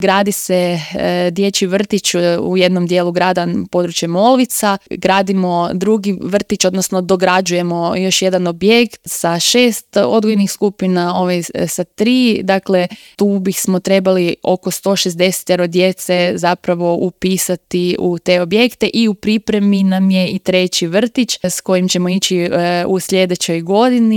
ZAGREB - U intervjuu Media servisa povodom rođendana grada Samobora gostovala je gradonačelnica Petra Škrobot.